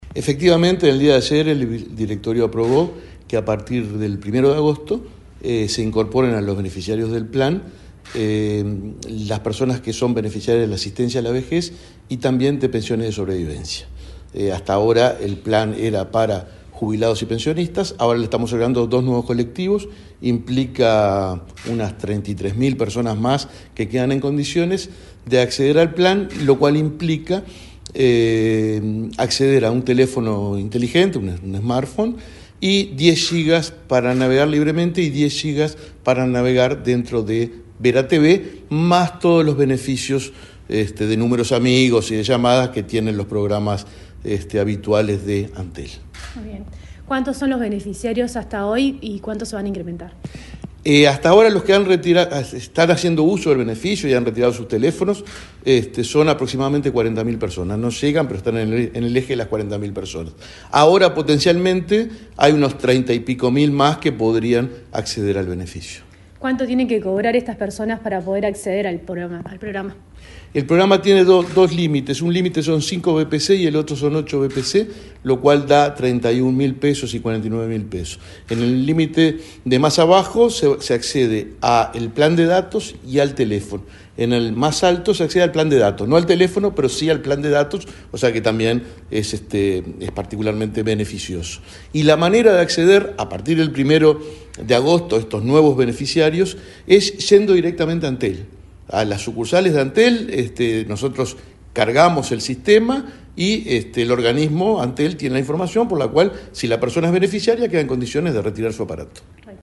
Entrevista al presidente del BPS, Alfredo Cabrera
El presidente del Banco de Previsión Social (BPS), Alfredo Cabrera, dialogó con Comunicación Presidencial, acerca de la decisión del directorio de